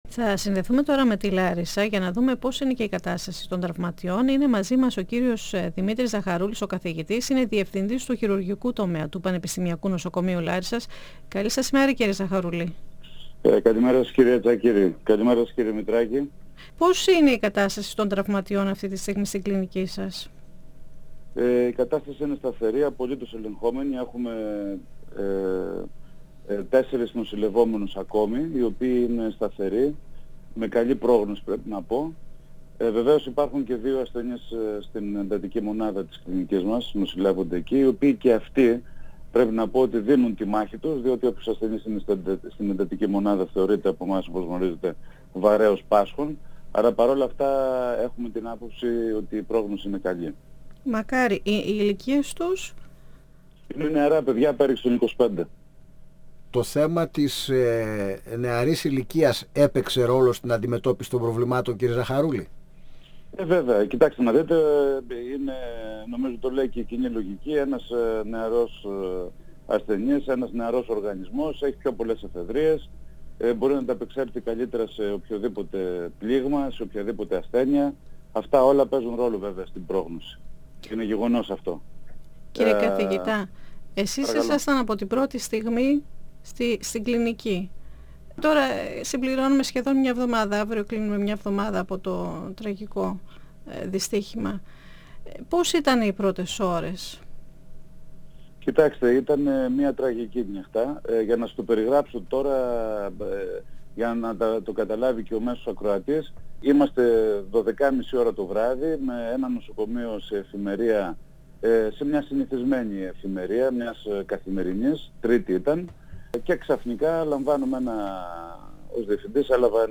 Ο καθηγητής περιέγραψε την επιχείρηση που στήθηκε στο νοσοκομείο τις πρώτες στιγμές της τραγωδίας και αναφέρθηκε στο πρωτόκολλο που εφαρμόστηκε. 102FM Συνεντεύξεις ΕΡΤ3